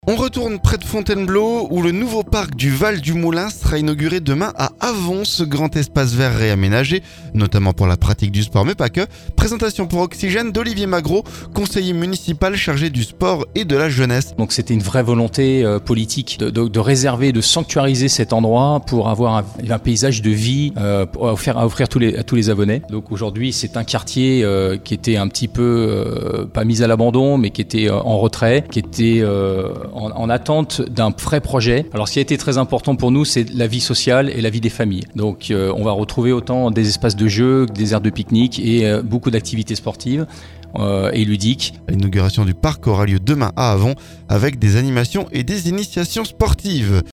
Le nouveau parc du val du Moulin inauguré samedi à Avon. Ce grand espace vert a été réaménagé, notamment pour la pratique du sport mais pas uniquement. Présentation pour Oxygène d'Olivier Magro, conseiller municipal chargé du sport et de la jeunesse.